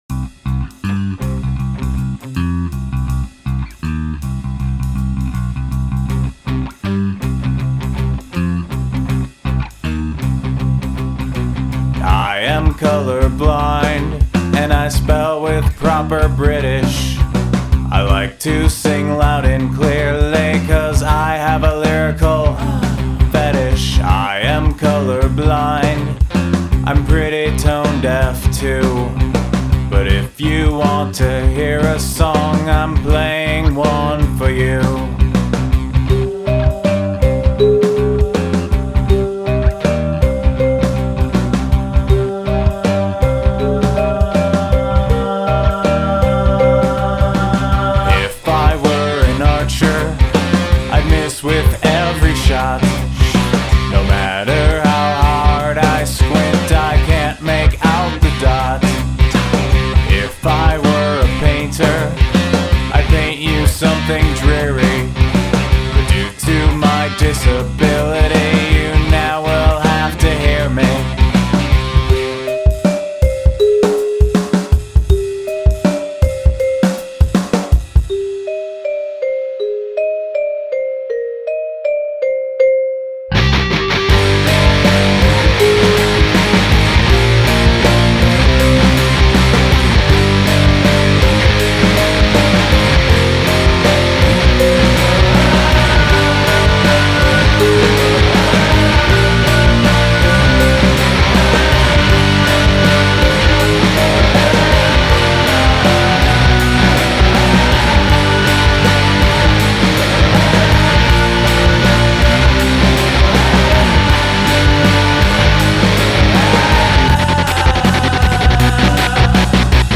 Song cannot have a chorus.